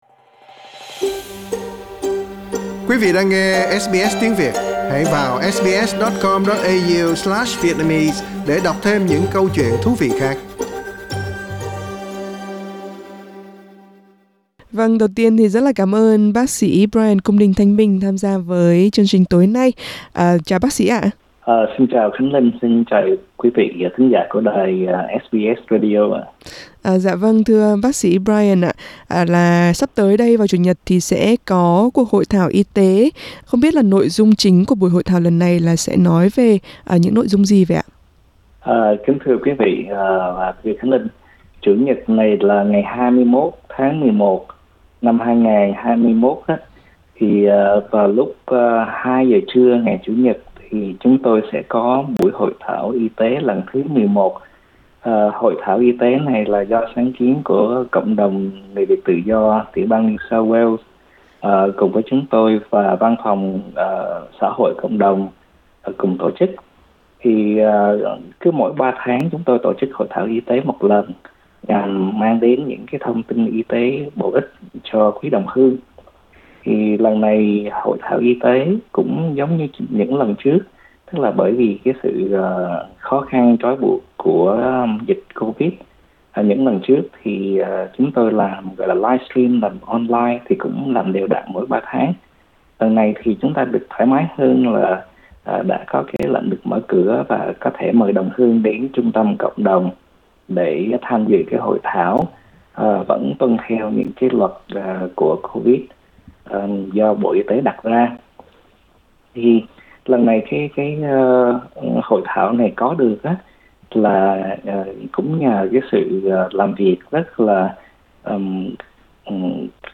Nói chuyện với SBS Việt ngữ